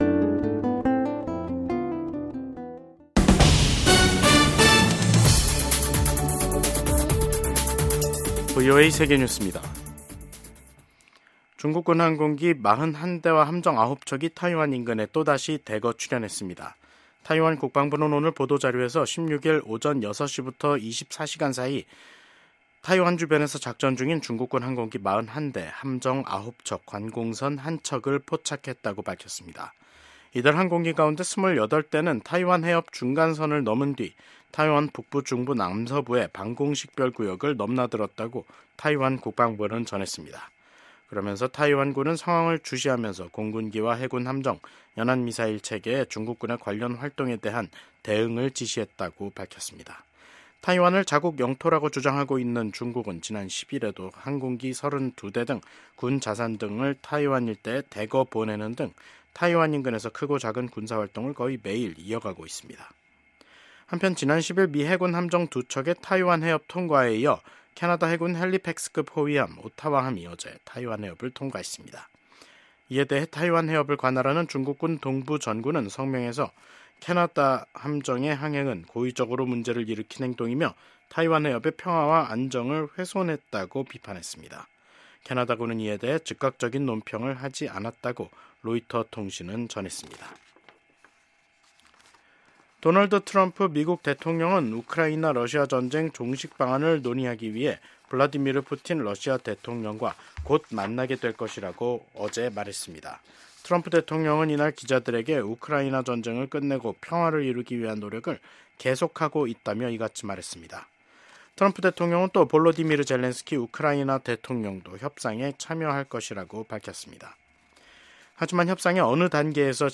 VOA 한국어 간판 뉴스 프로그램 '뉴스 투데이', 2025년 2월 17일 3부 방송입니다. 미국과 한국, 일본의 외교장관들이 북한의 완전한 비핵화에 대한 확고한 의지를 재확인했습니다. 15일 열린 미한일 외교장관 회의에서 북한의 완전한 비핵화 목표를 확인한 데 대해 도널드 트럼프 행정부가 투트랙 대북전략을 구사할 것이라는 관측이 나왔습니다. 미국의 전술핵무기를 한반도에 배치할 경우 위기 상황에서 생존 가능성이 낮다고 전 미국 국방부 차관보가 밝혔습니다.